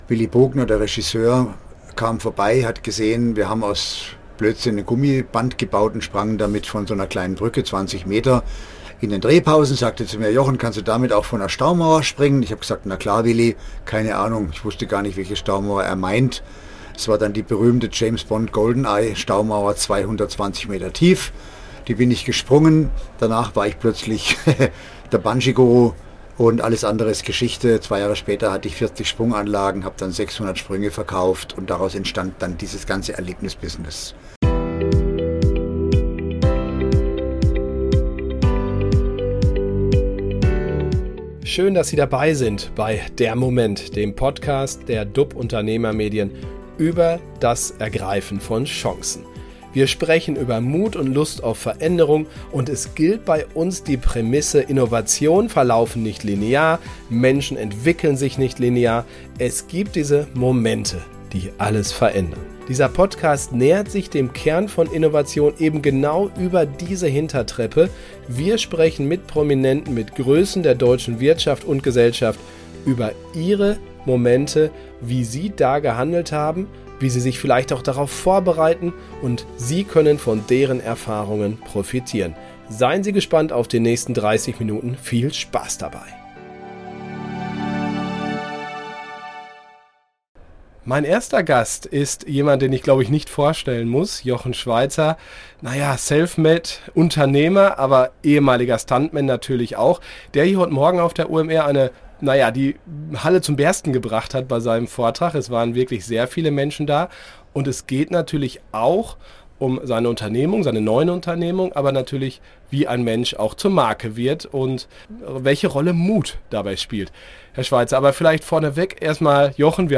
Der Moment - Gespräche über das Ergreifen von Chancen - Über den Unterschied zwischen Mut und Dummheit – mit Jochen Schweizer